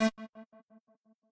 synth1_5.ogg